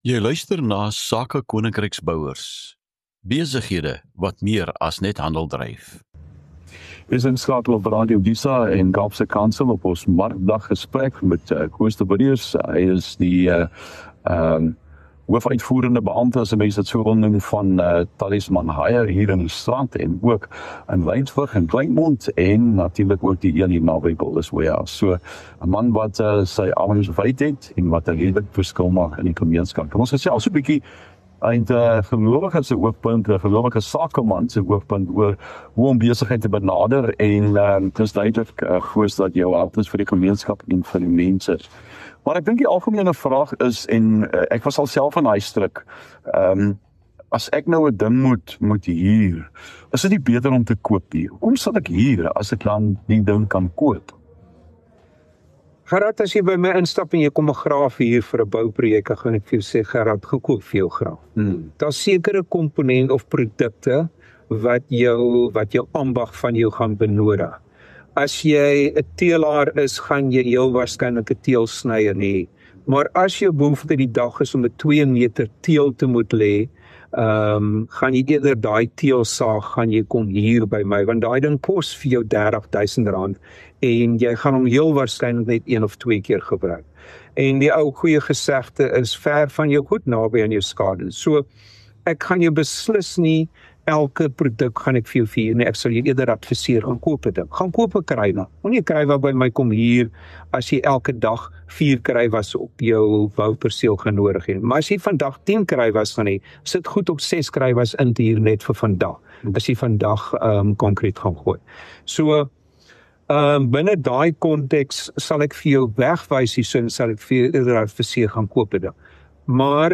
’n Insiggewende en praktiese gesels oor entrepreneurship, innovasie en ondersteuning van die boubedryf.